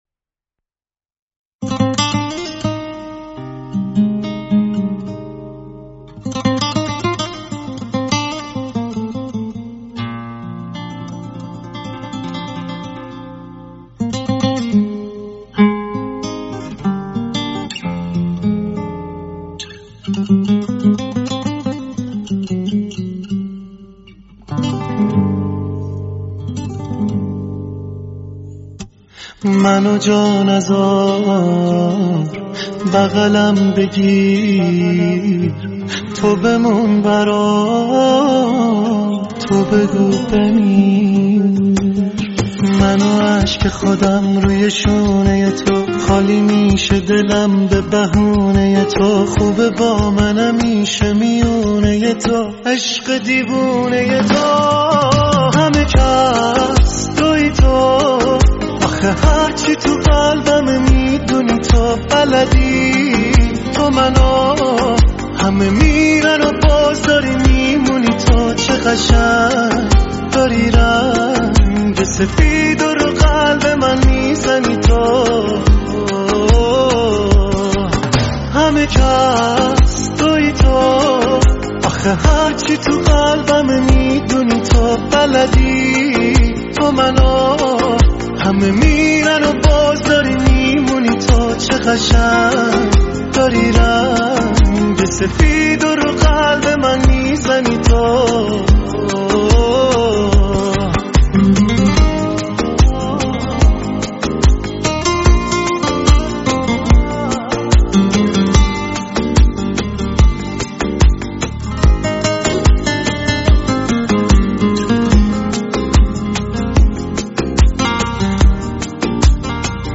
تیتراژ ابتدایی